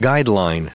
Prononciation du mot guideline en anglais (fichier audio)
Prononciation du mot : guideline